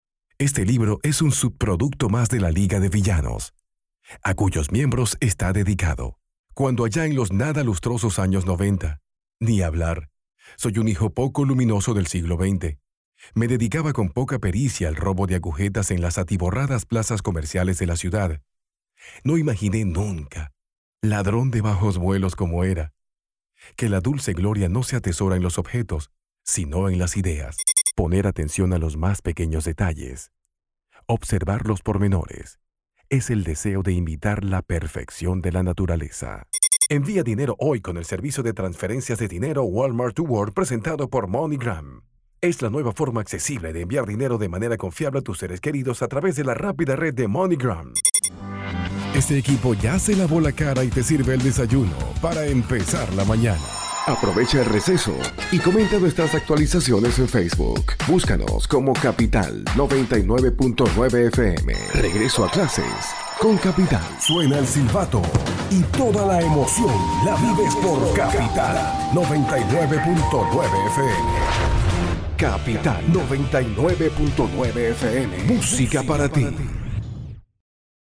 Sprechprobe: Industrie (Muttersprache):
Voice over, professional speaker for more than 20 years, own recording studio and availability 24/7.